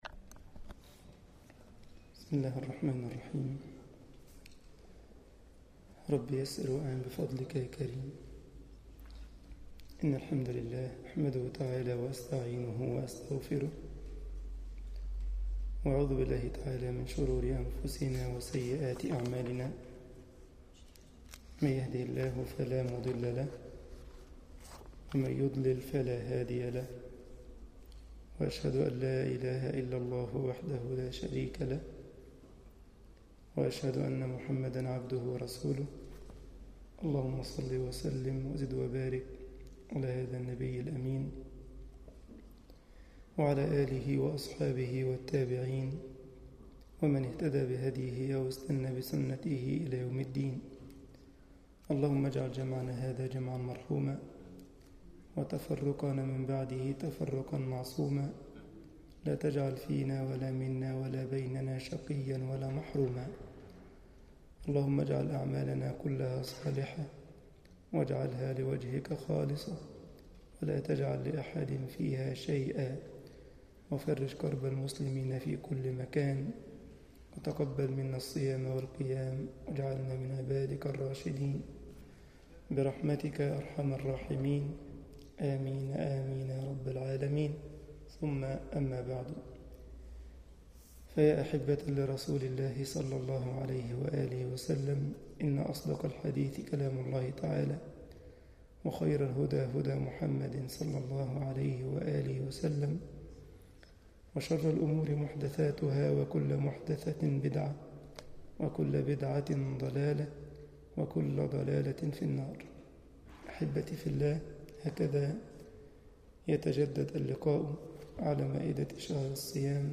مسجد الجمعية الإسلامية بالسارلند ـ ألمانيا درس 12 رمضان 1433هـ